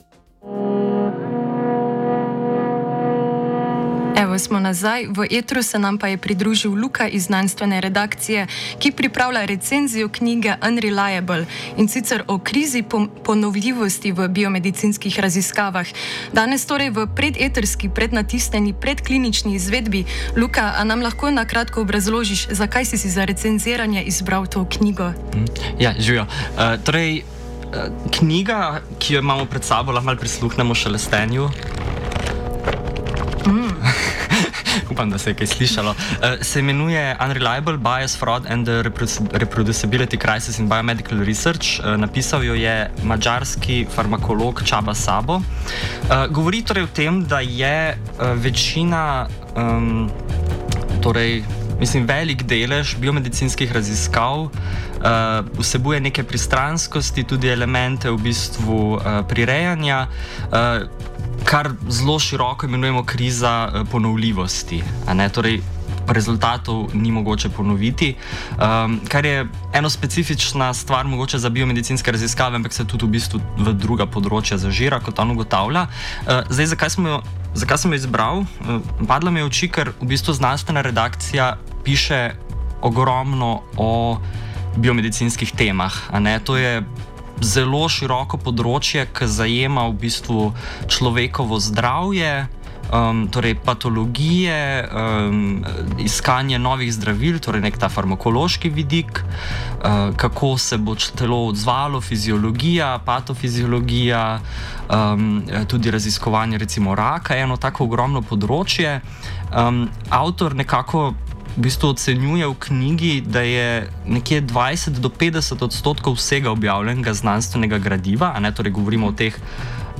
pogovor-pred-recenzijo.mp3